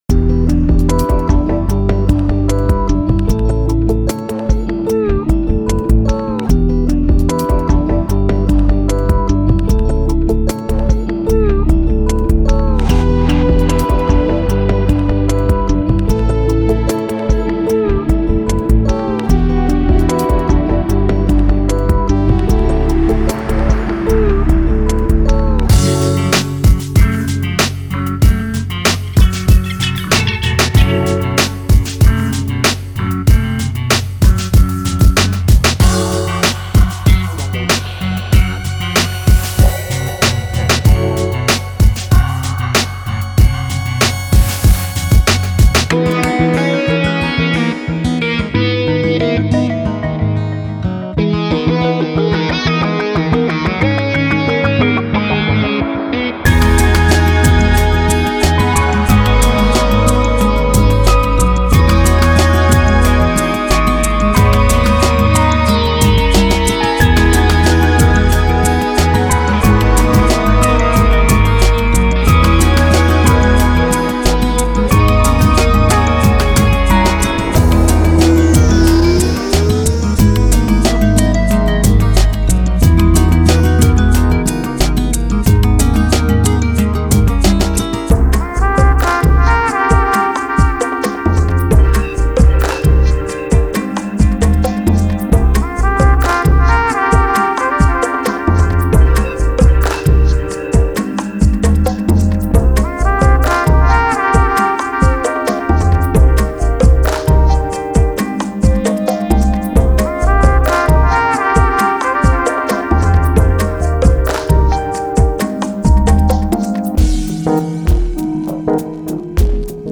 – 75 BPM – 133 BPM